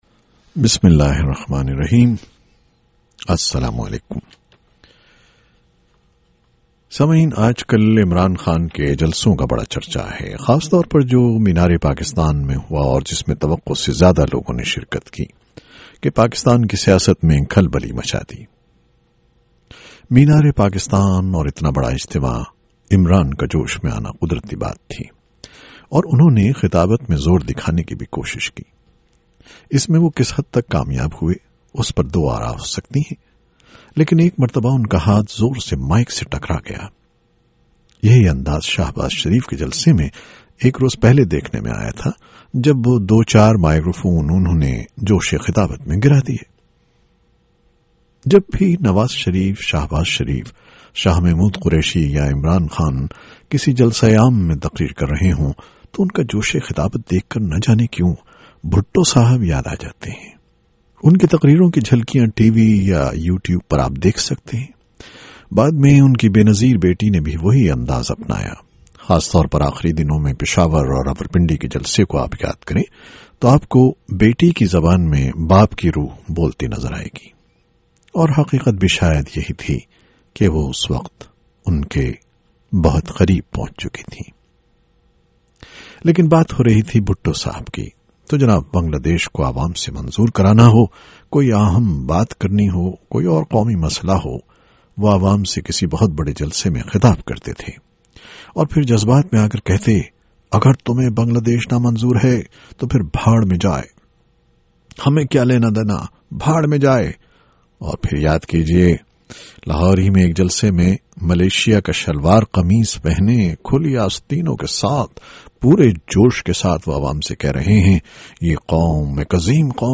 سوچا کہ کیوں نہ اپنی آواز میں یادوں کی چار گذشتہ اقساط کو ریکارڈ کردوں، تاکہ بہت سے وہ لوگ جو سن نہیں پائے، وہ اسے ایک ہی نشست میں سن لیں